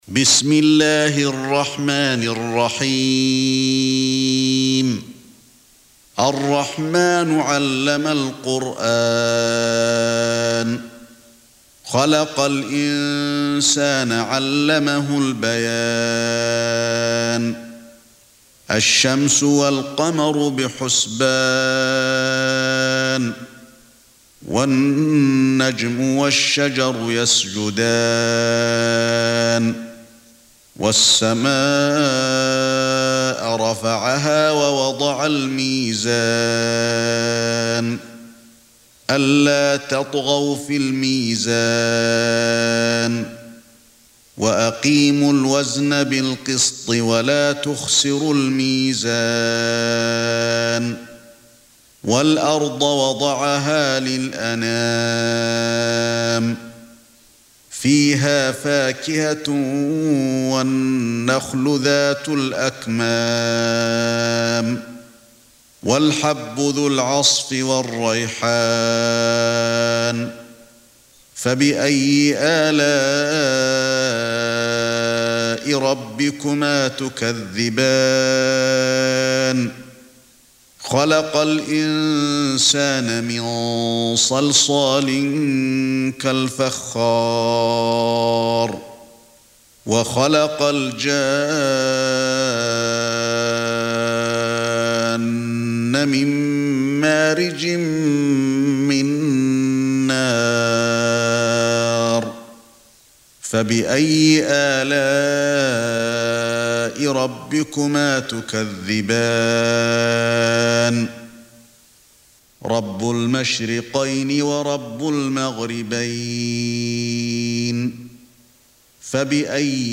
55. Surah Ar-Rahm�n سورة الرحمن Audio Quran Tarteel Recitation
Surah Sequence تتابع السورة Download Surah حمّل السورة Reciting Murattalah Audio for 55.